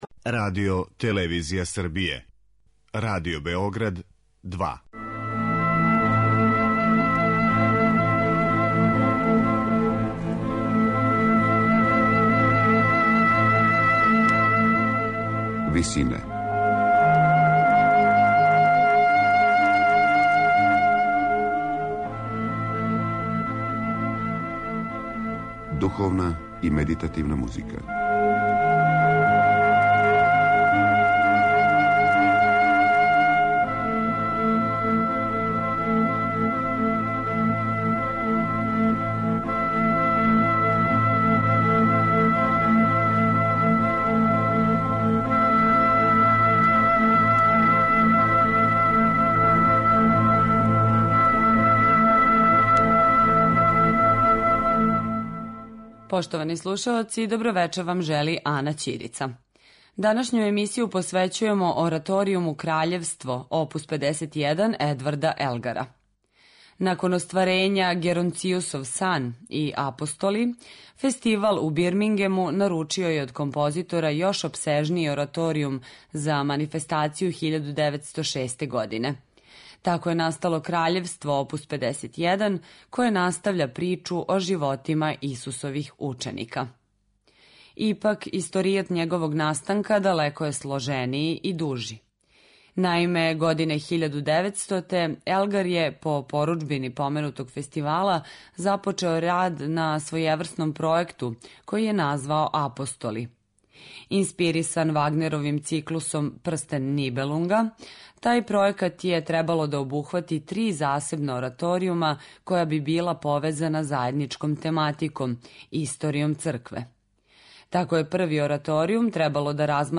у ВИСИНАМА представљамо медитативне и духовне композиције аутора свих конфесија и епоха.